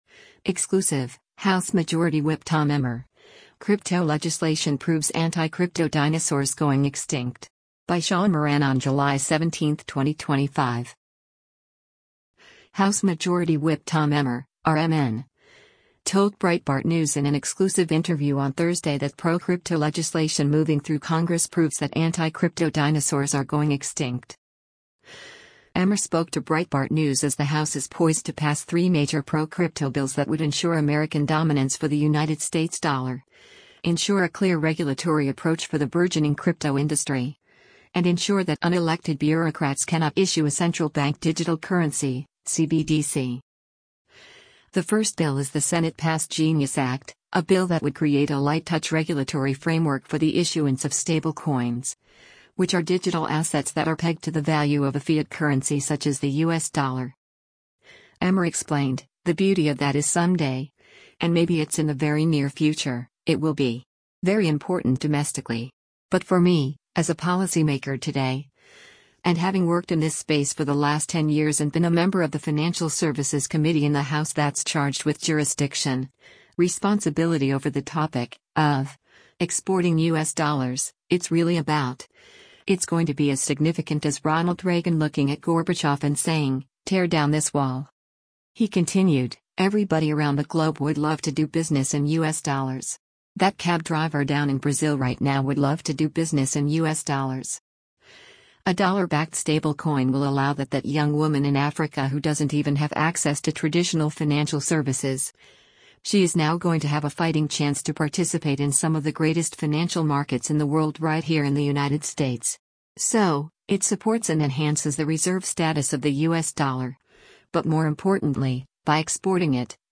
House Majority Whip Tom Emmer (R-MN) told Breitbart News in an exclusive interview on Thursday that pro-crypto legislation moving through Congress proves that anti-crypto dinosaurs are going extinct.